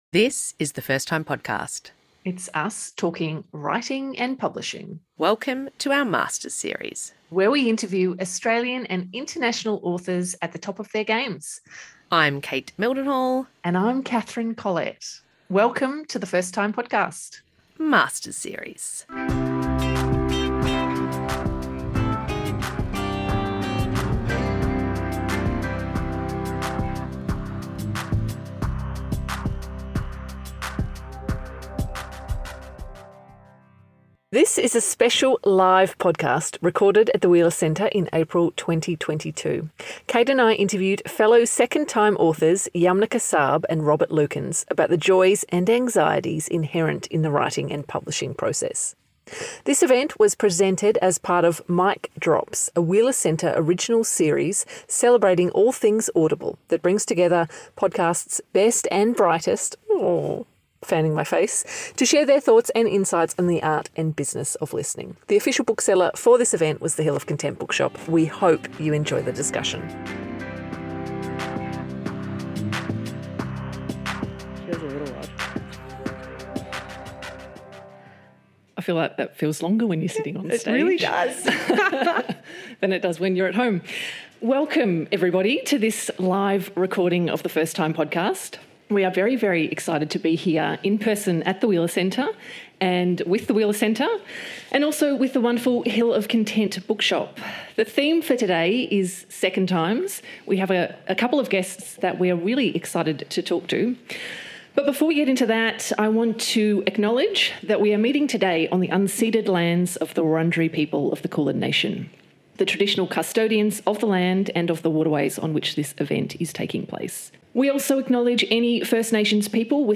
This is a special live podcast, recorded at the Wheeler Centre in April